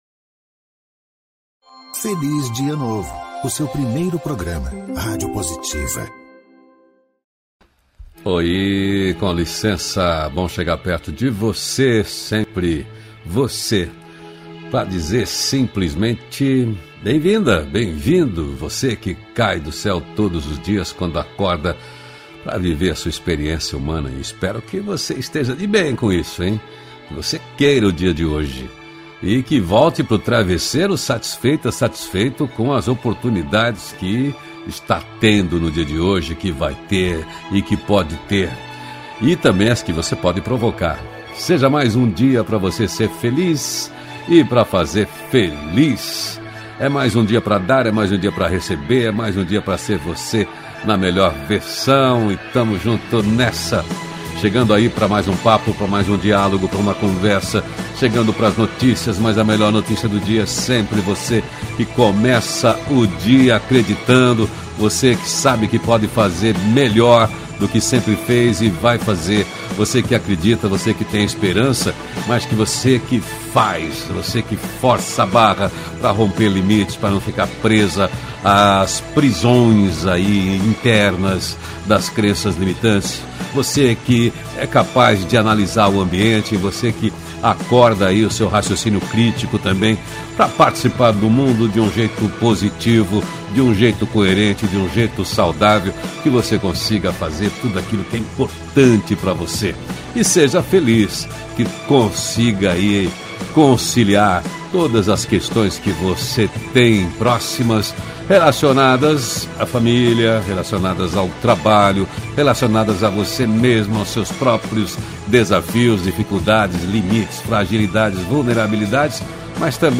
Conversa com quem tem o que dizer de bom.
O programa é um contraponto leve ao noticiário hard predominante na mídia tradicional de rádio e tv. O Feliz Dia Novo, é uma revista descontraída e inspiradora na linguagem de rádio (agora com distribuição via agregadores de podcast), com envolvimento e interatividade da audiência via redes sociais.